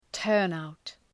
Προφορά
{‘tɜ:rnaʋt}